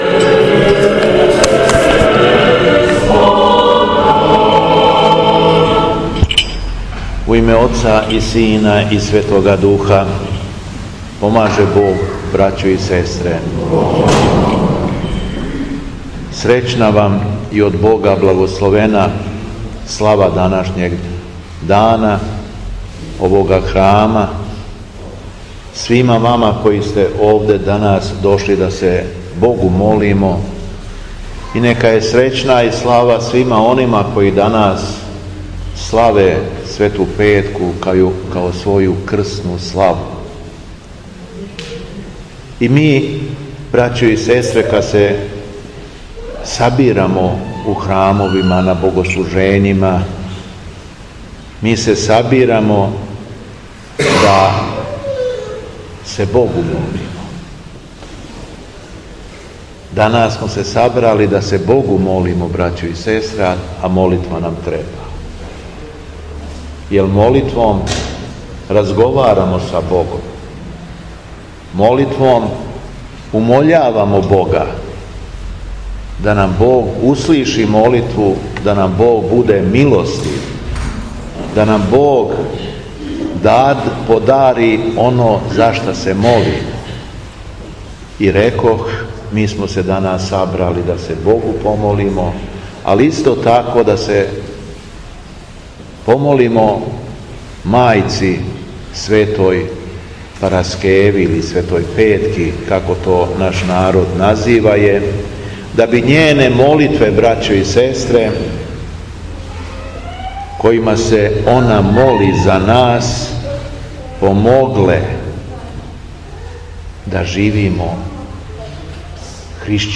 Беседа
у храму Преподобне мати Параскеве